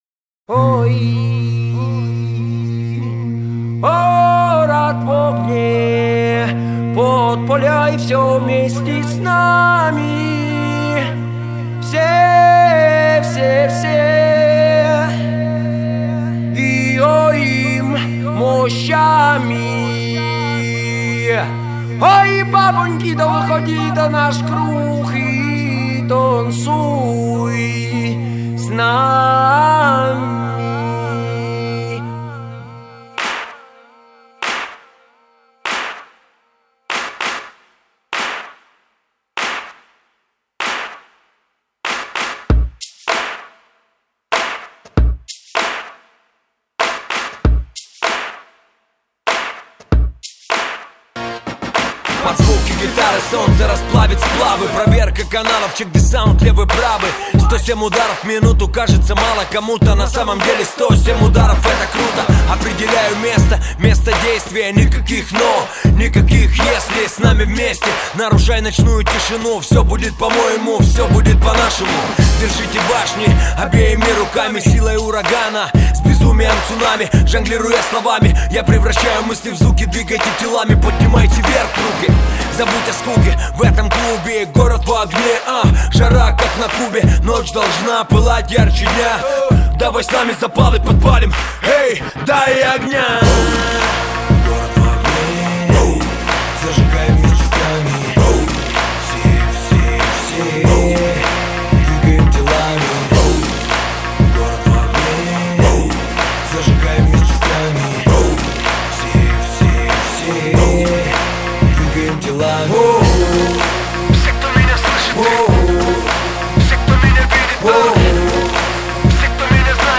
Категория: Пацанский рэп